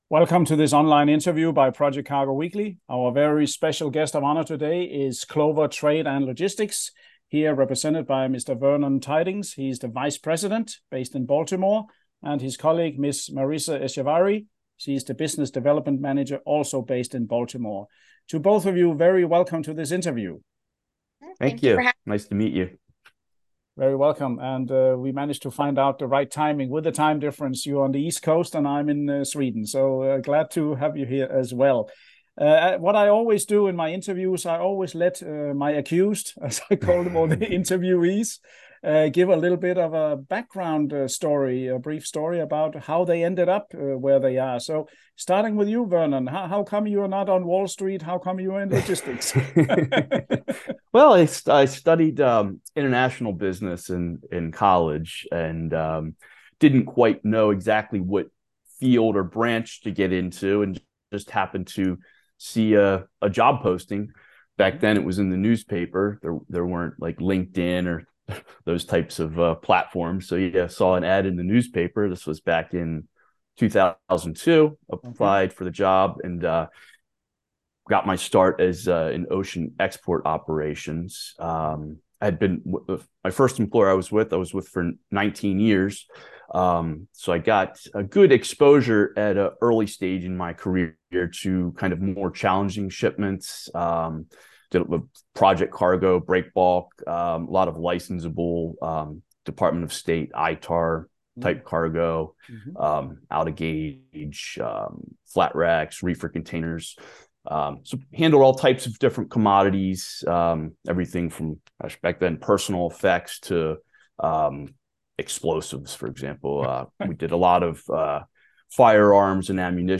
Video InterviewClover Trade & Logistics